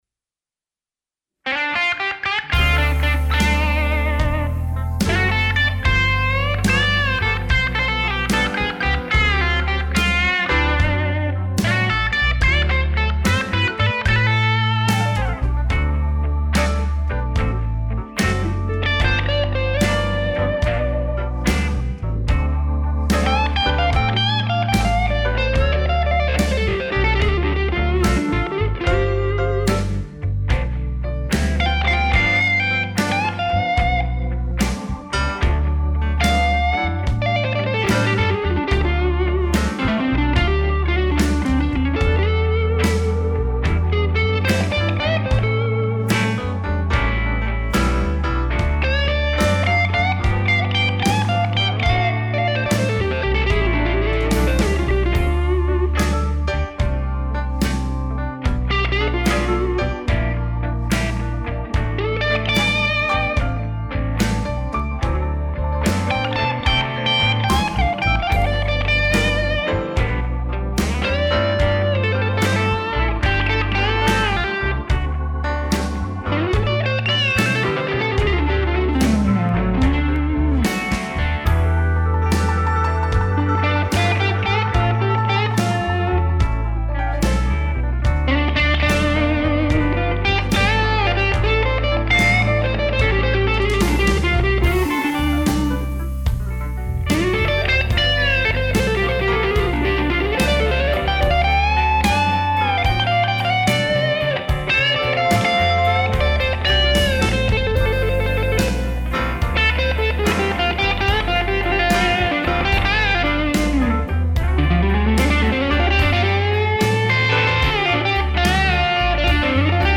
Редкий пример современного инструментала этой мелодии.